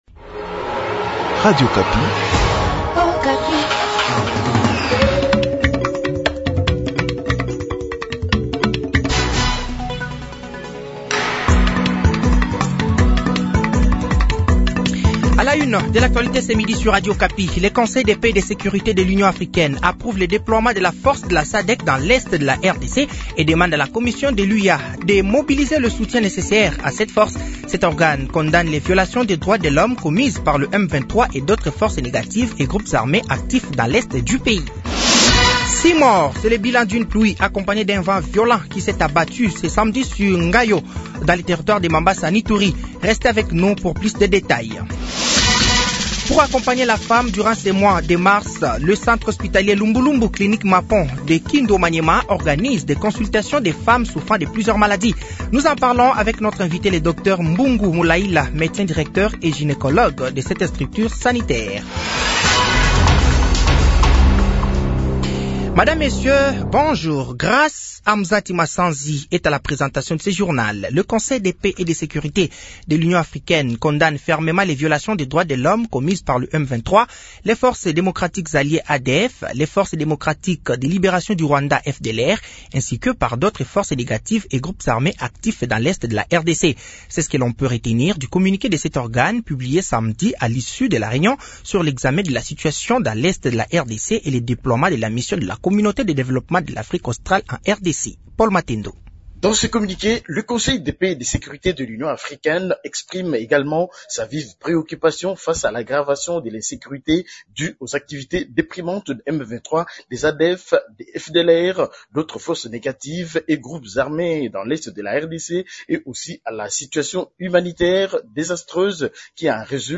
Journal français de 12h de ce dimanche 10 mars 2024